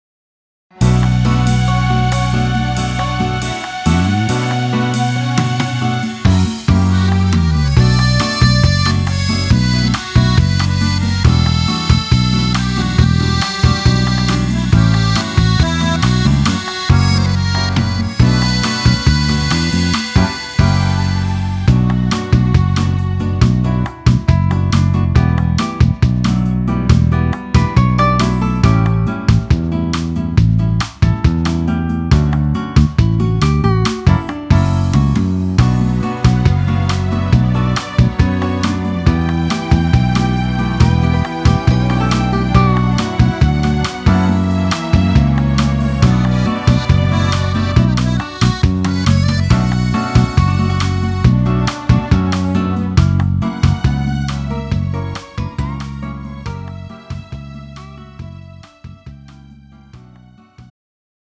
음정 -1키 3:43
장르 가요 구분 Pro MR